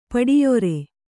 ♪ paḍiyore